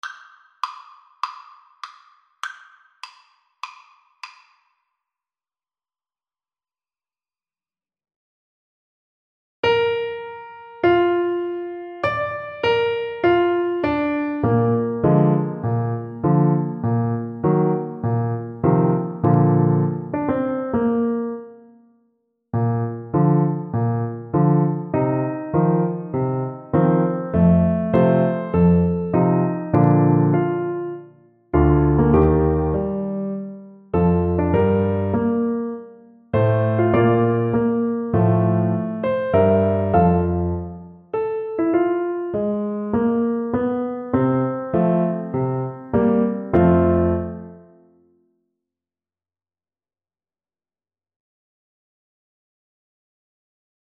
Bb major (Sounding Pitch) (View more Bb major Music for Trombone )
Andante
Classical (View more Classical Trombone Music)